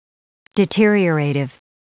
Prononciation du mot deteriorative en anglais (fichier audio)
deteriorative.wav